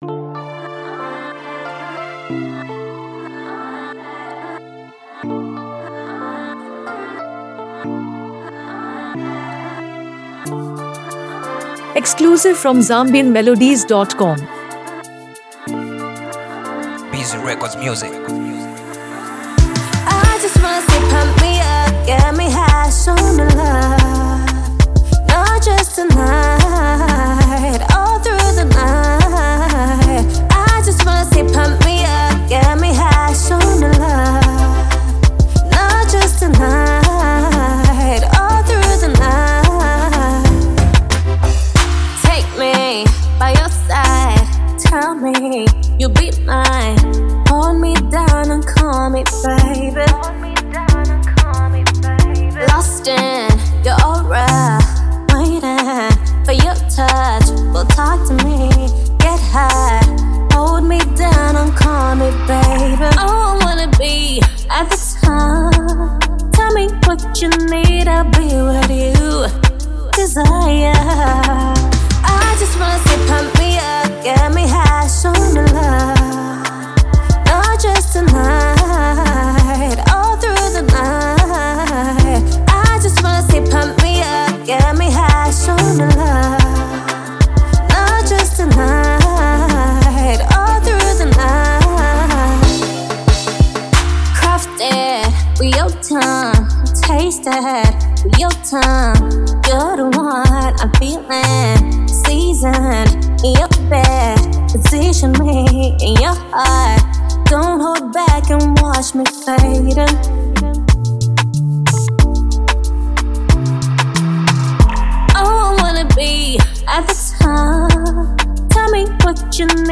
love song
Known for her captivating voice and heartfelt delivery
The track carries a vibrant Afro-pop and R&B fusion